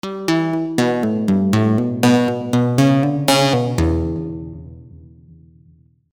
flip with custom shape 2